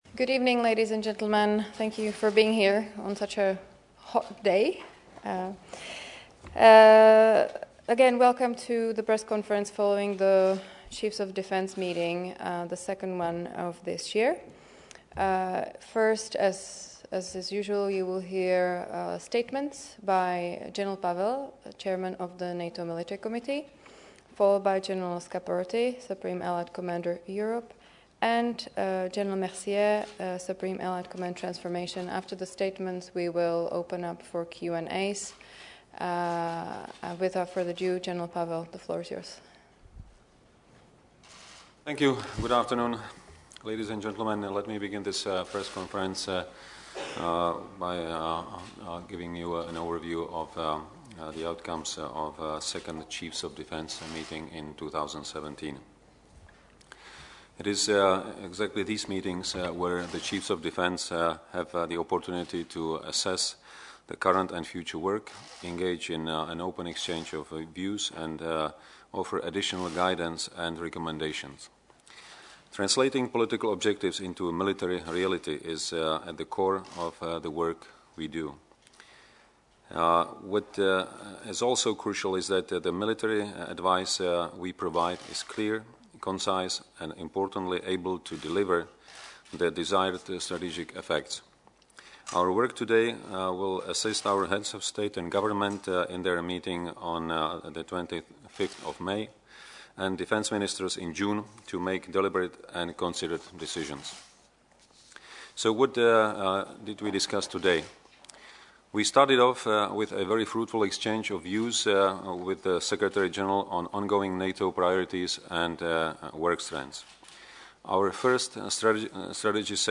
ENGLISH - Joint press conference with Chairman of the Military Committee General Petr Pavel, by Supreme Allied Commander Europe General Curtis M. Scaparrotti and by Supreme Allied Commander Transforma
with opening statements by Chairman of the Military Committee, General Petr Pavel, by Supreme Allied Commander Europe - General Curtis M. Scaparrotti and by Supreme Allied Commander Transformation - General Denis Mercier, followed by Q&A session Photos Opening remarks Questions & answers English French